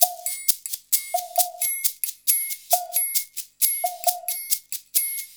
Index of /90_sSampleCDs/USB Soundscan vol.56 - Modern Percussion Loops [AKAI] 1CD/Partition A/04-FREEST089